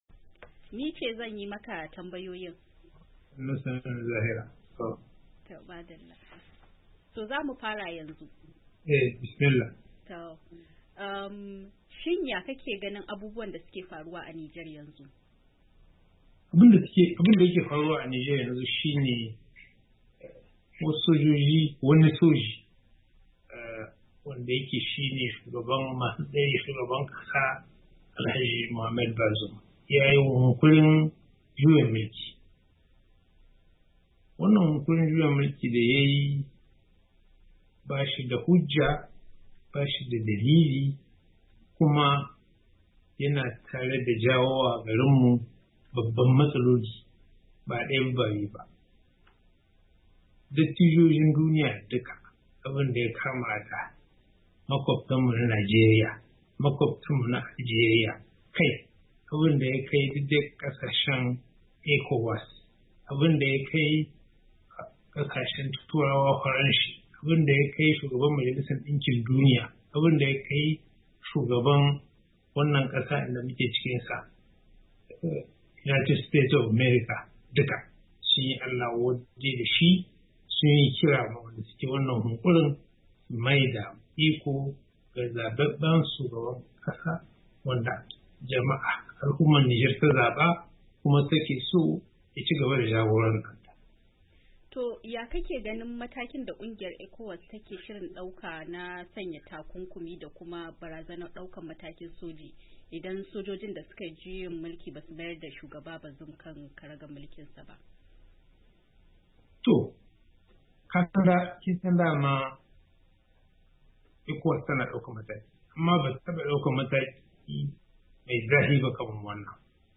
A hirarsa da Muryar Amurka, Jakadan Nijar a Amurka, Mamadou Kiari Liman-Tinguiri, ya ce duk da'awar yin wannan kasada ta juyin mulki ba ta da wata hujja ko kadan.